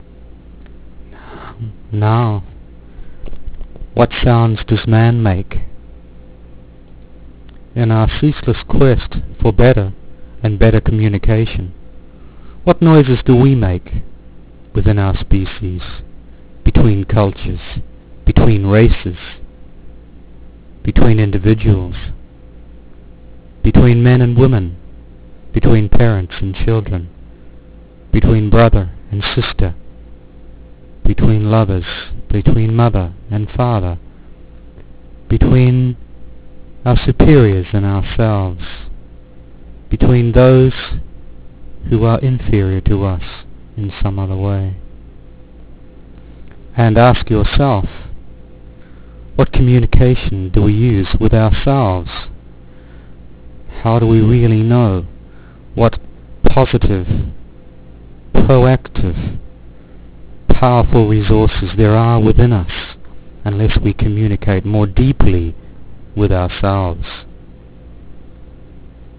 RESOURCE-HUMAN NOISES.wav